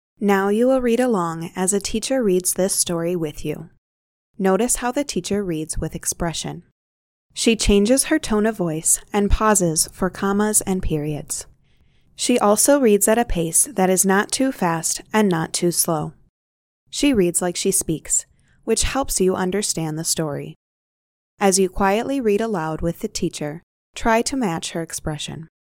audio instruction prompting them to pay attention to the narrator's expression.